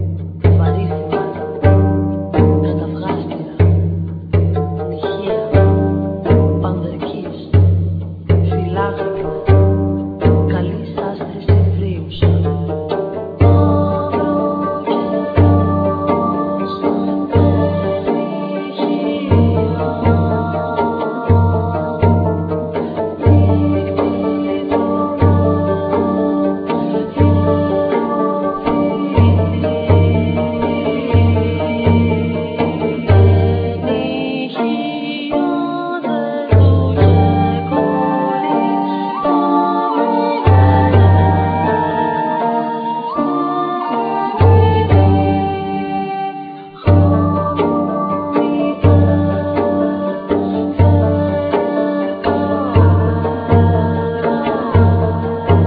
Ancient greek instruments